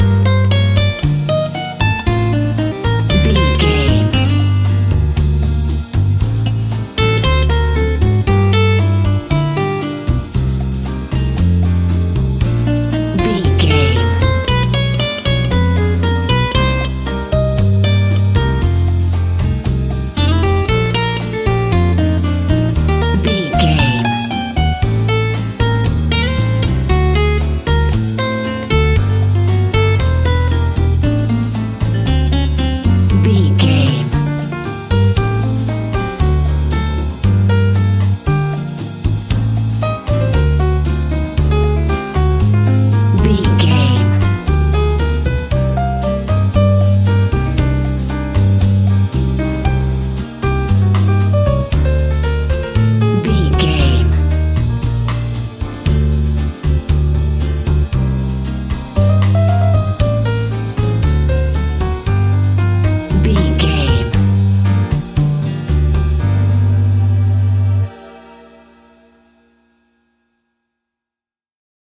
An exotic and colorful piece of Espanic and Latin music.
Aeolian/Minor
flamenco
romantic
maracas
percussion spanish guitar